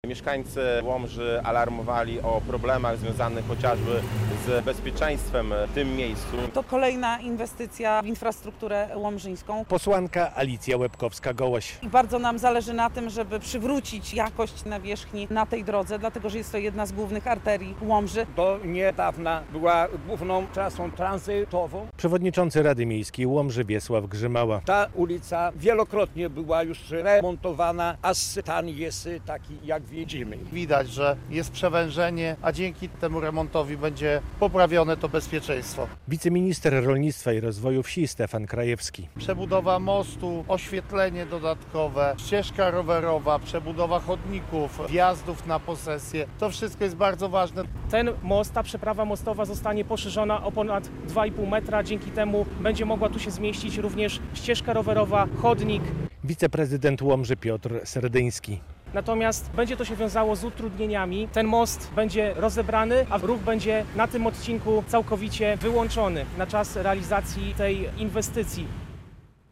Przebudowa mostu na Łomżyczce - relacja